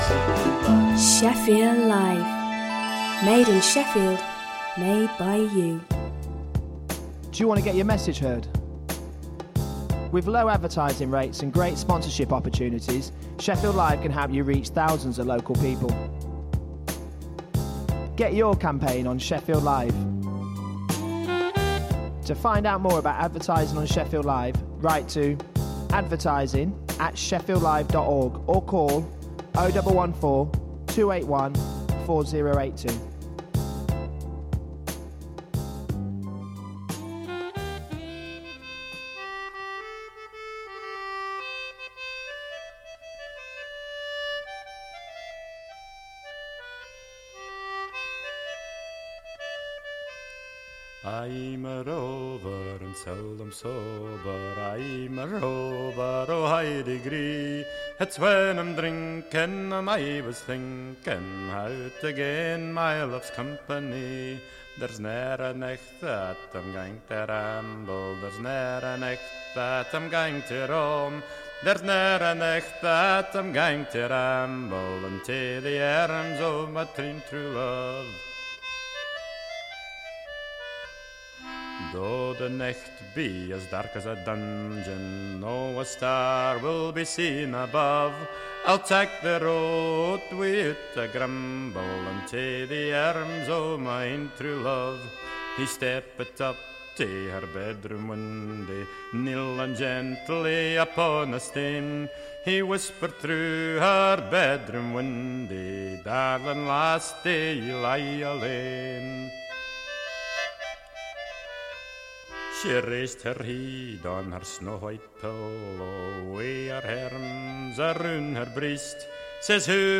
Write Radio is a two hour radio show which showcases new and local writing from the people of South Yorkshire.